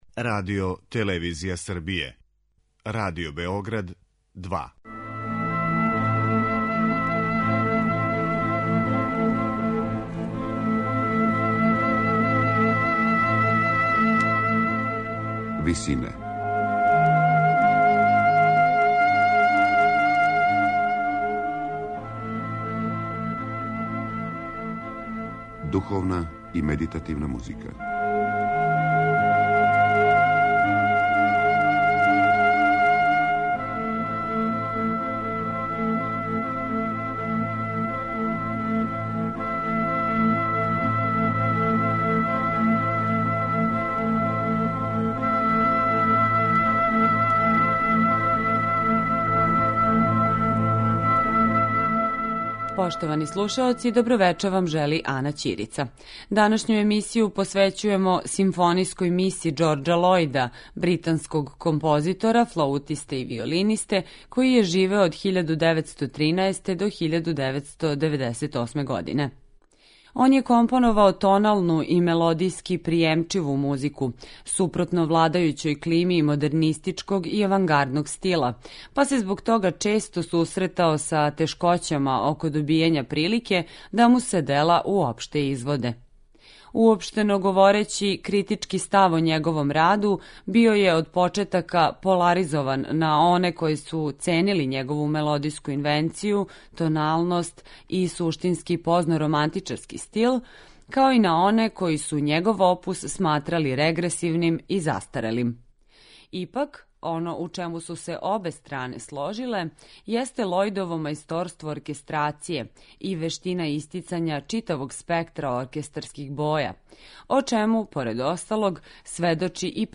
Симфонијскa мисa британског композитора Џорџа Лојда.
медитативне и духовне композиције
У првој овонедељној емисији посвећеној духовној и медитативној музици, слушаћете Симфонијску мису британског композитора Џорџа Лојда.